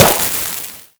player_dodge_success_alt.wav